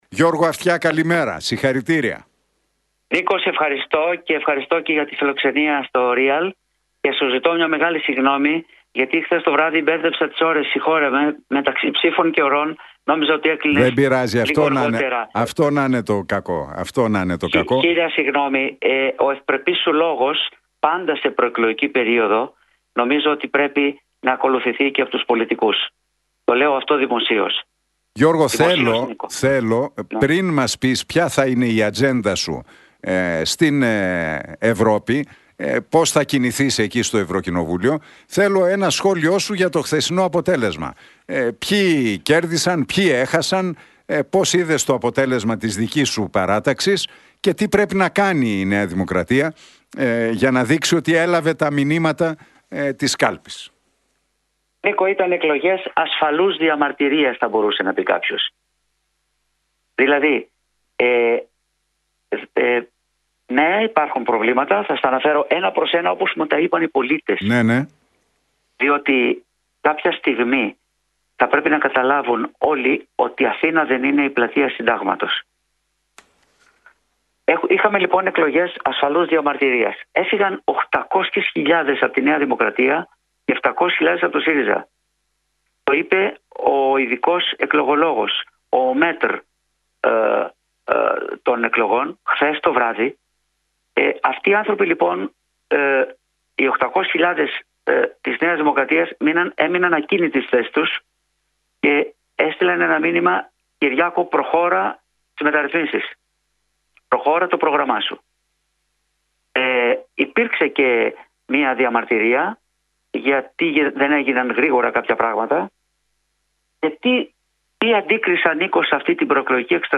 Εκλογές ασφαλούς διαμαρτυρίας χαρακτήρισε τις Ευρωεκλογές ο Γιώργος Αυτιάς μιλώντας στον Realfm 97,8 και την εκπομπή του Νίκου Χατζηνικολάου.